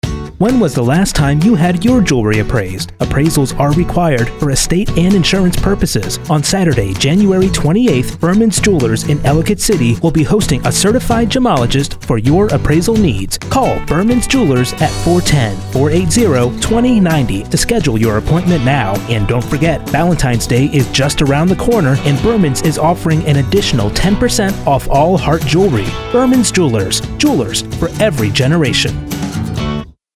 Berman’ s Jewelers radio commercial 2